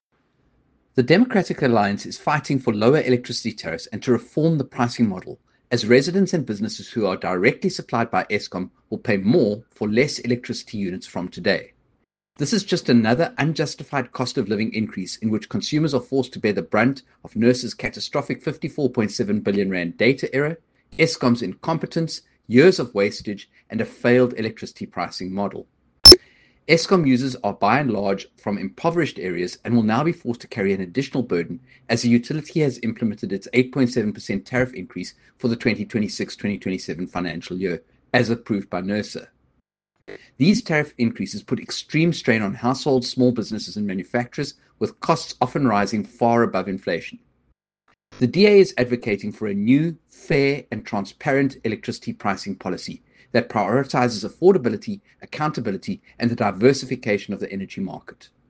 Soundbite by Kevin Mileham MP.